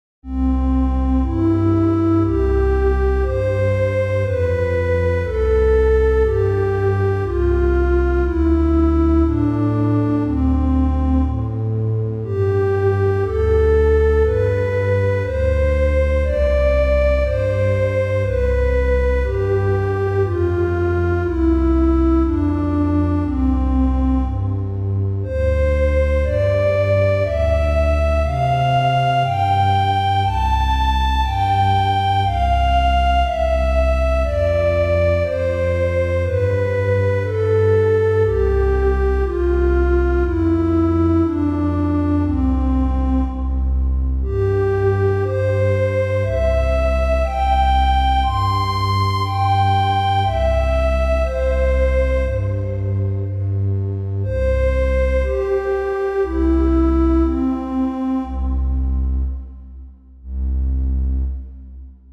幻想的でゆっくりな曲です。
BPM60 いやし